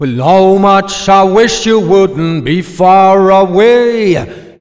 OLDRAGGA4 -L.wav